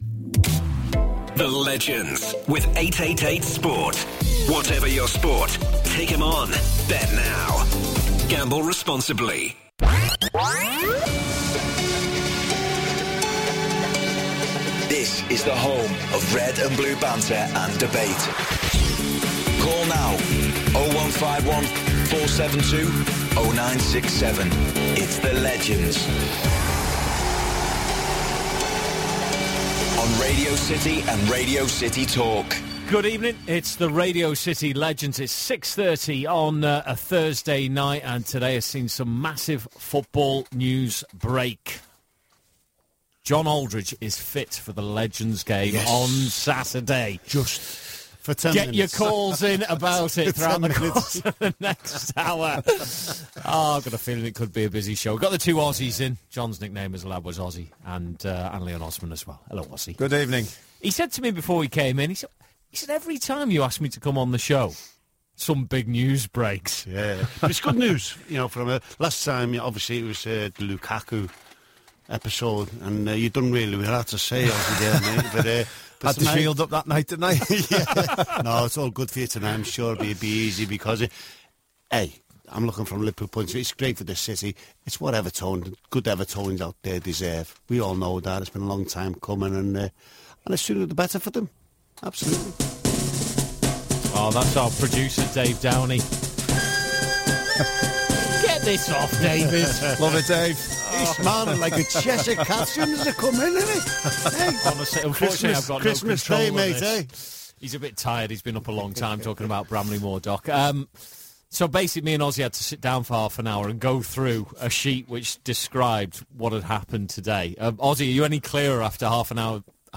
The lads also discuss Liverpool and take more of your calls.